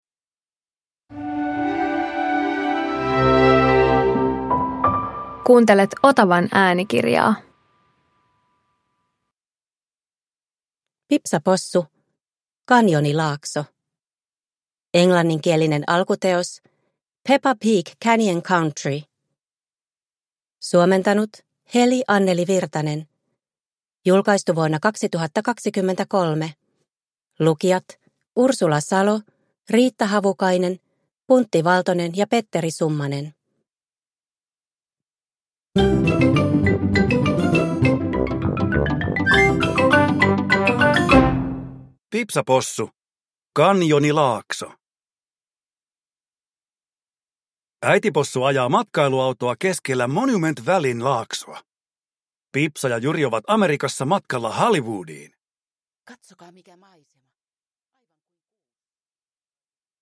Pipsa Possu - Kanjonilaakso – Ljudbok